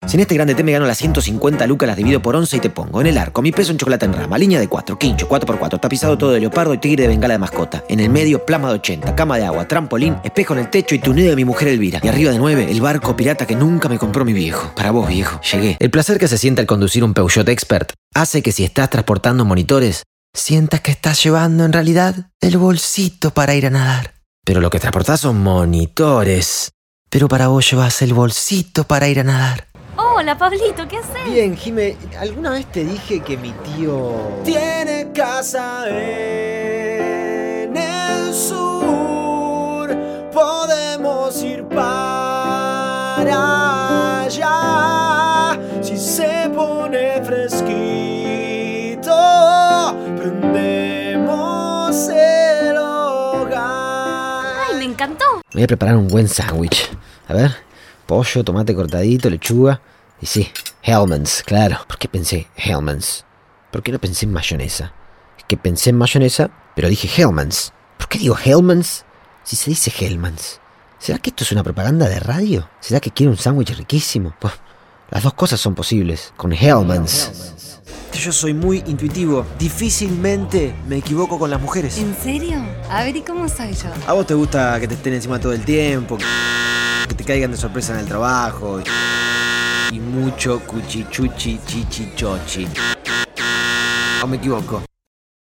Espagnol (argentin)
Chaleureux
Amical